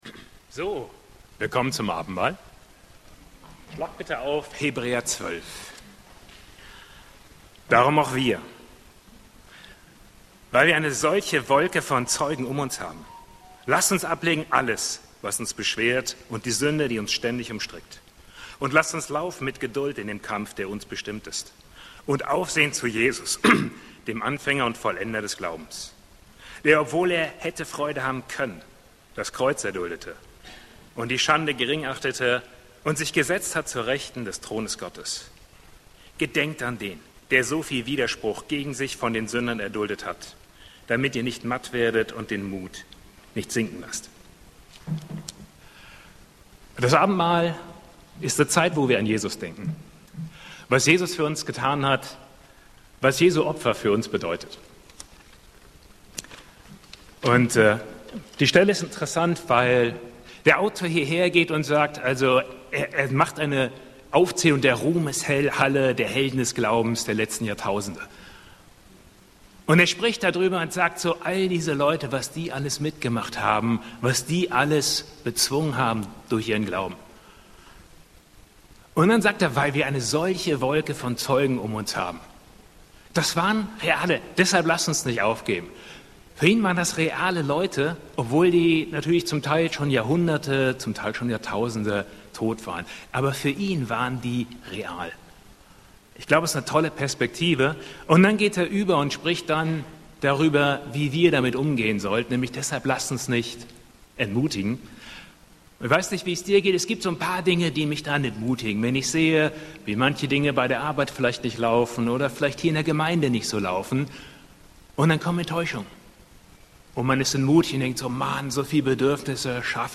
E-Mail Details Predigtserie: Abendmahl Datum